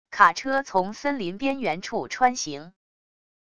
卡车从森林边缘处穿行wav音频